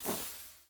Minecraft Version Minecraft Version snapshot Latest Release | Latest Snapshot snapshot / assets / minecraft / sounds / block / candle / extinguish2.ogg Compare With Compare With Latest Release | Latest Snapshot
extinguish2.ogg